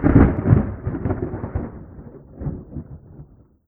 tenkoku_thunder_close04.wav